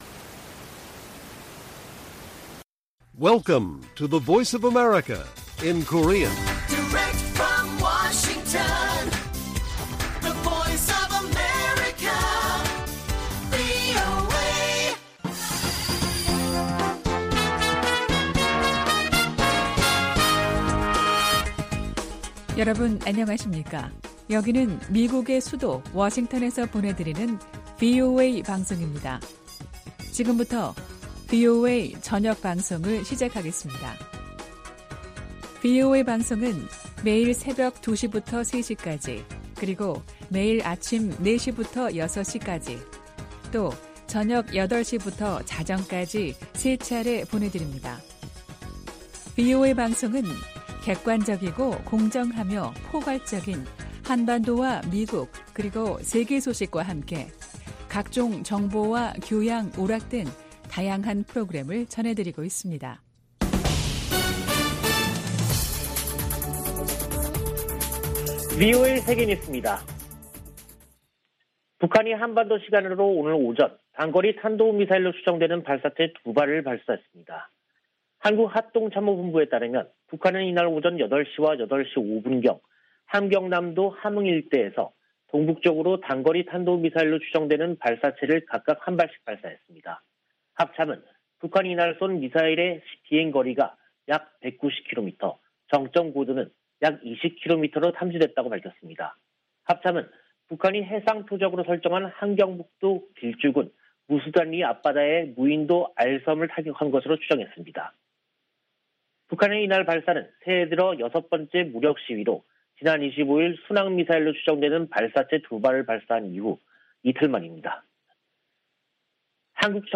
VOA 한국어 간판 뉴스 프로그램 '뉴스 투데이', 2022년 1월 27일 1부 방송입니다. 북한이 또 단거리 탄도미사일로 추정되는 발사체 2발을 동해상으로 쐈습니다.